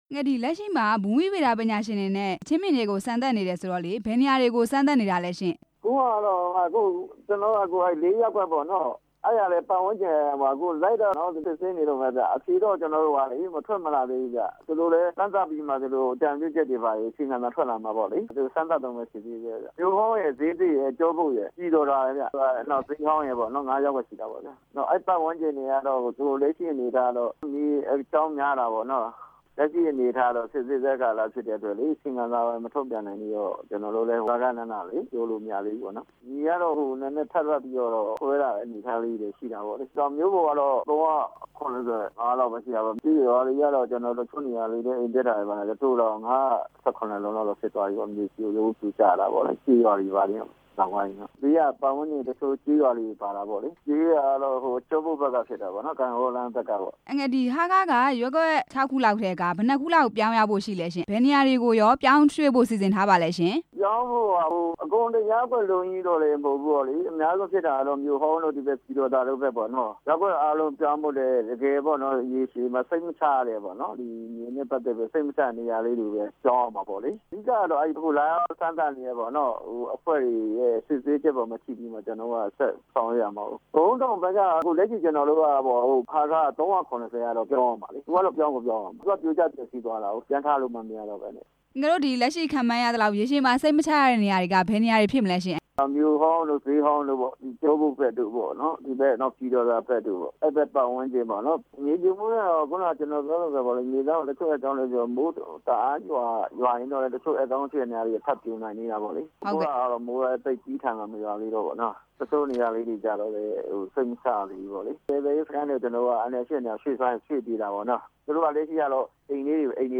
ဆက်သွယ်မေးမြန်းခဲ့ပါတယ်။